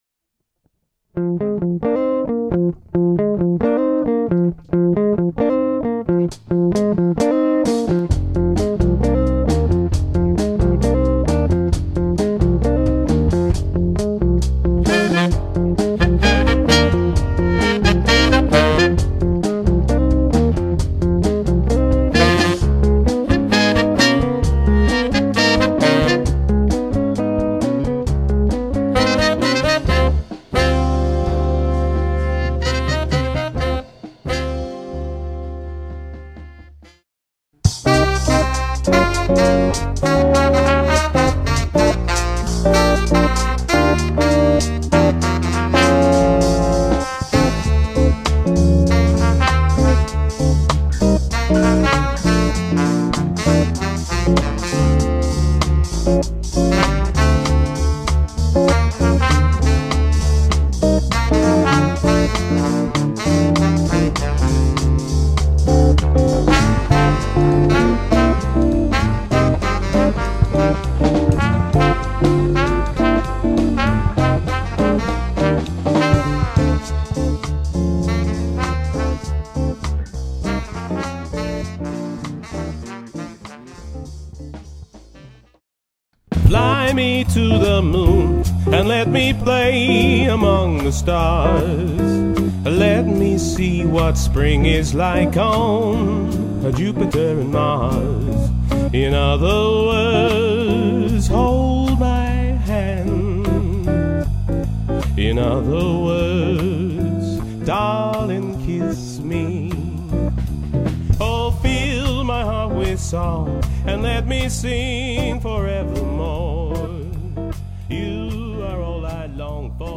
female vocalist
vocals and bass
trumpet, trombone, keyboard and bass
alto and tenor Saxophones
Electric Guitar